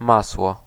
Ääntäminen
Ääntäminen RP : IPA : /ˈbʌ.tə/ UK : IPA : [ˈbʌt.ə] US : IPA : [ˈbʌɾ.ə] Tuntematon aksentti: IPA : /ˈbʌ.təɹ/ US : IPA : /ˈbʌ.tɚ/ IPA : [ˈbʌɾɚ] Northern and Midland England, Wales, Scotland: IPA : /ˈbʊ.tə/